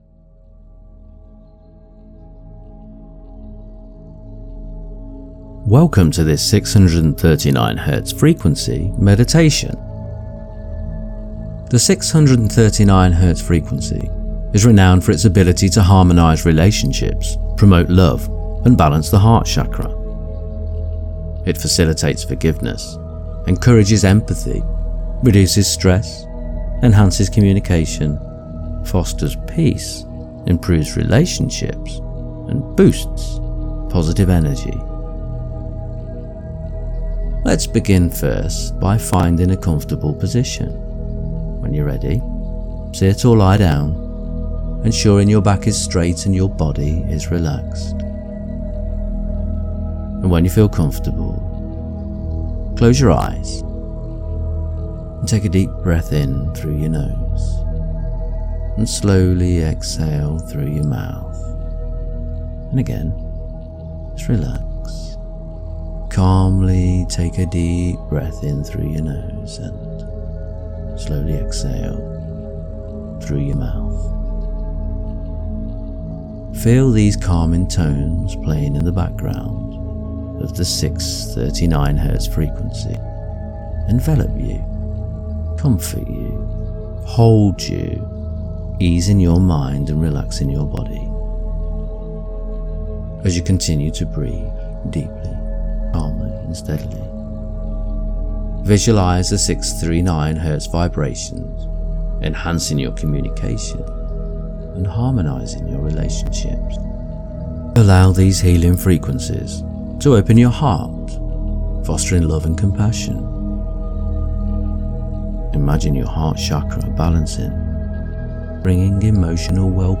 639-meditation.mp3